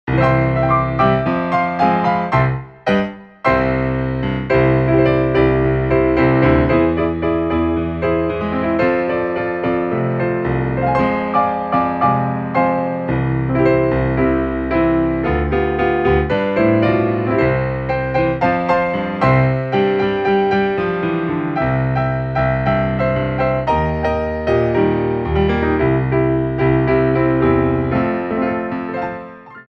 33 Inspirational Ballet Class Tracks
Tendus
4/4 (16x8)